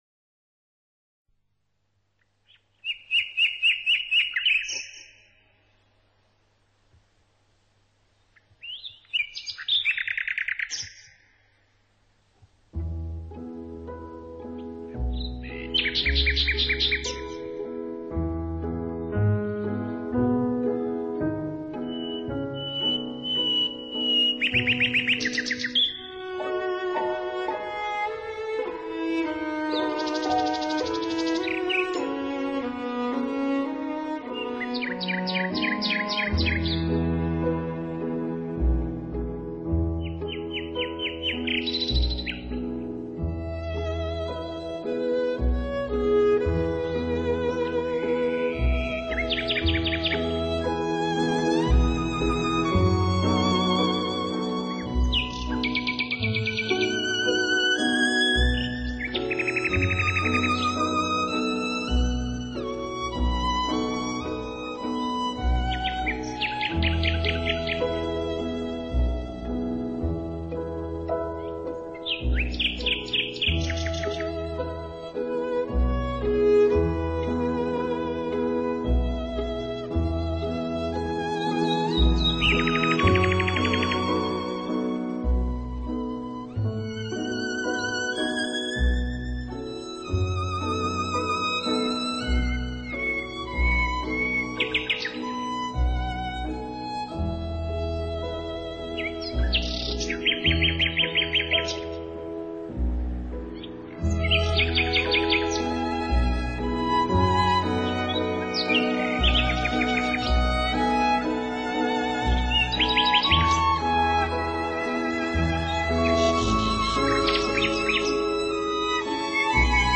音乐类型：Classic 古典
第一首的夜莺啼声真是美极了，配合着背景里的水声，好像
雅又闲适。二支小提琴、一支大提琴与一支低音提琴，还有一座钢琴，它们的乐器质感
非常的好，音质也非常的美，除了小提琴在高把位时略嫌尖一点外，其余无懈可击。
With The Wonderful Song Of The Nightingale